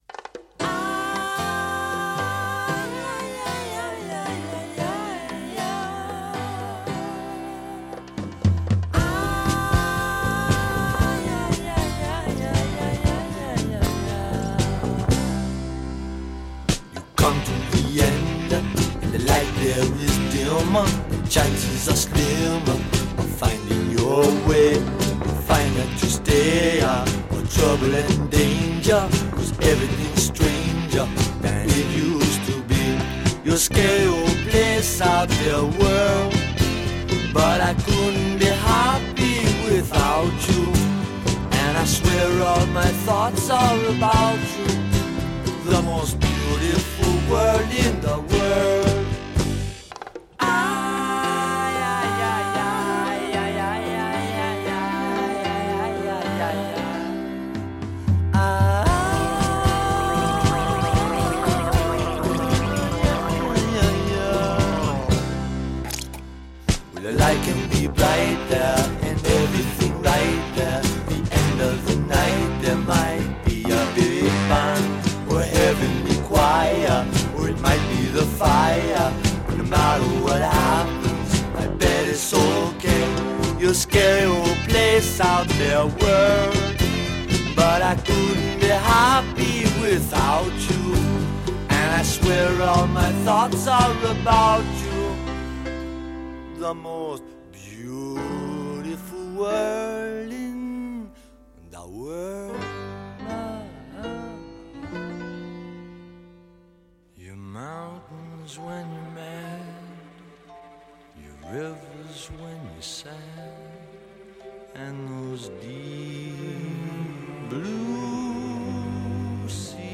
The album’s closing track is a cheeky tribute to…the world.